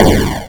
explosion.aiff